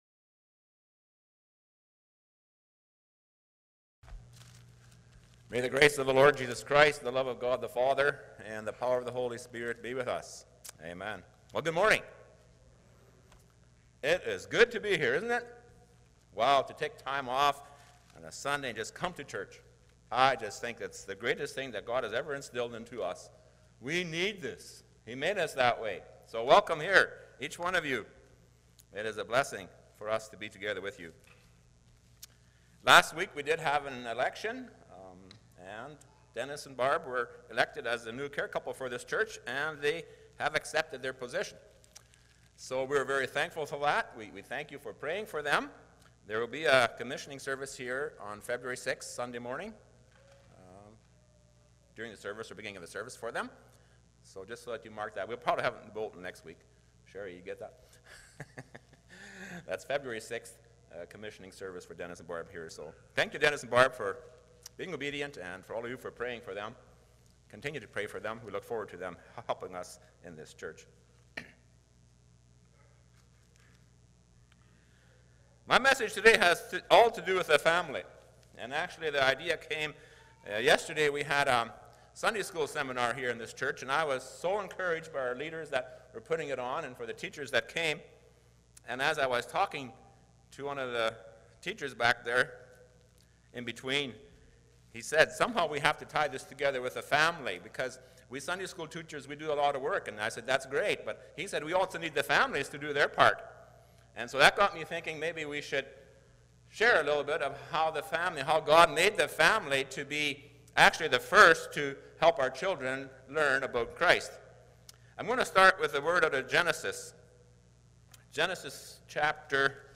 2:24-25 Service Type: Sunday Morning « Church Bible Study